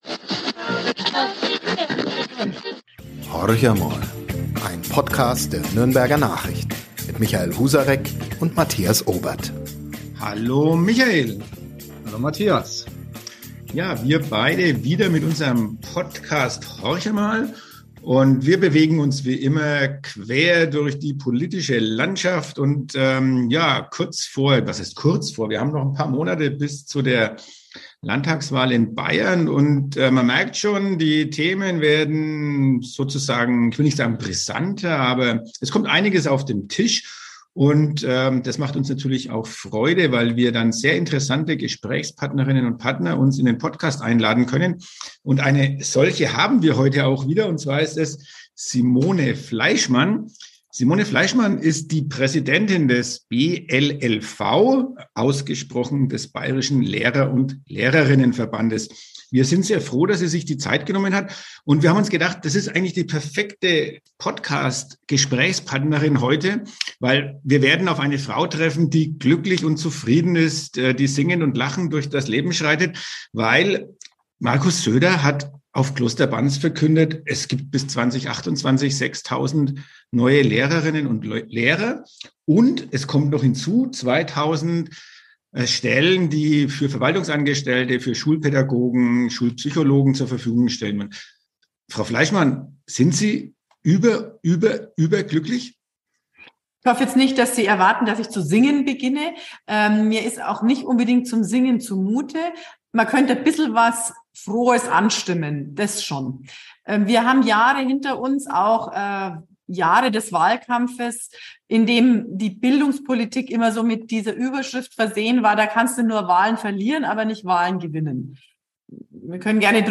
Doch es schwingt auch Skepsis in ihrer Stimme bei der Frage mit, ob die Umsetzung des als „Kraftpaket“ titulierten Programms tatsächlich kommt.